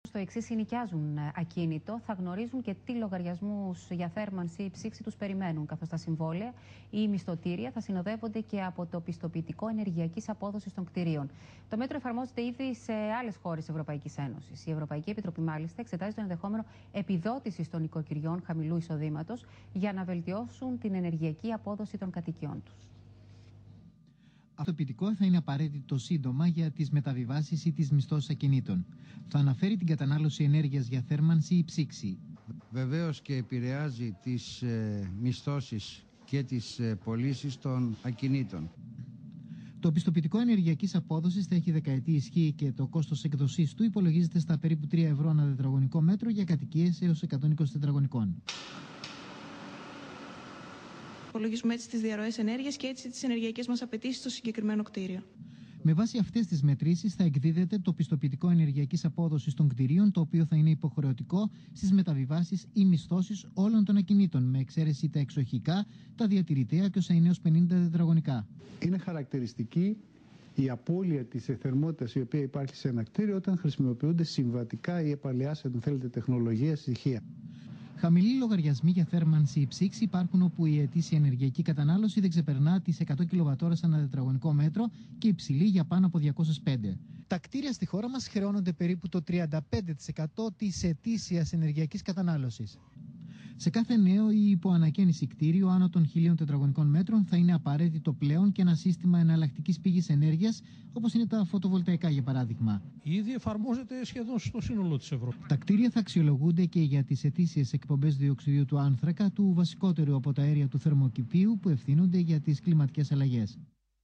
Δελτίο ειδήσεων (ώρα έναρξης εκπομπής: 21:00)
Ηθοποιοί: Εκφωνήτρια ειδήσεων [ΕΕ], Δημοσιογράφος [ΔΜ], Υπεύθυνος ακινήτων[ΥΑ], Εμπειρογνώμονας [ΕΓ], Μηχανικός [ΜΧ], Πωλητής φωτοβολταϊκών [ΠΦ]